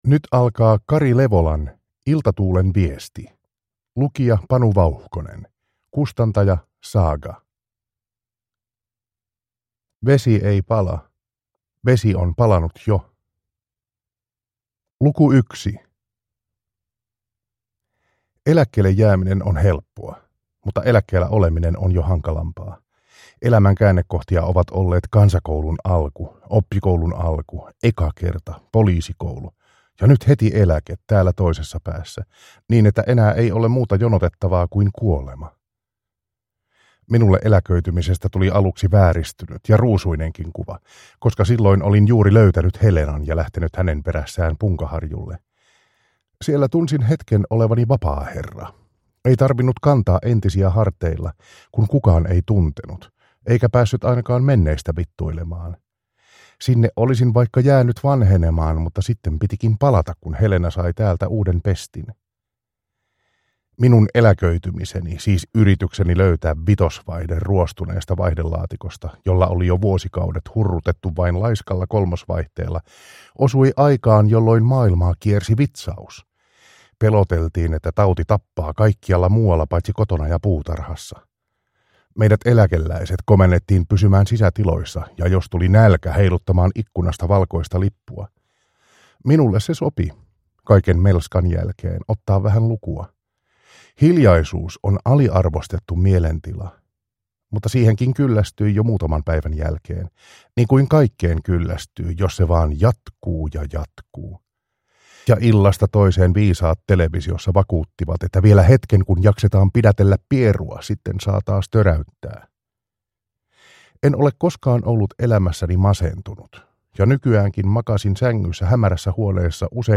Iltatuulen viesti / Ljudbok